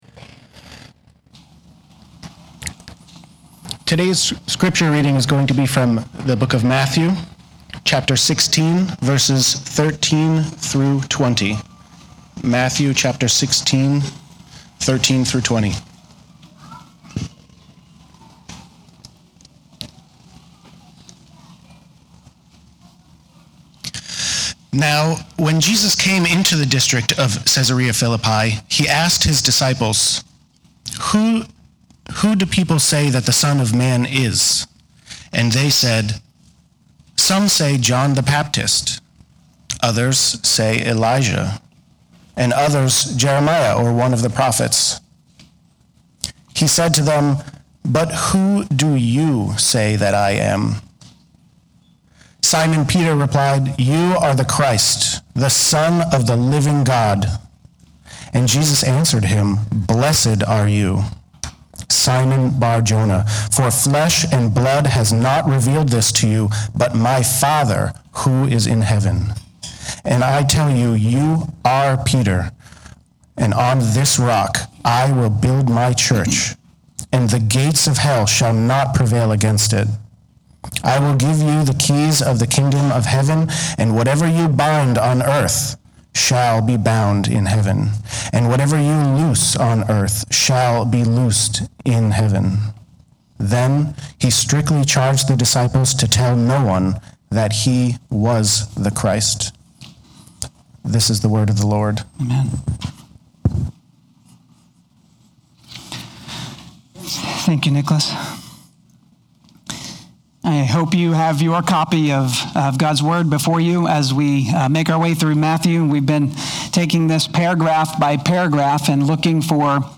Sermons | Hope Christian Church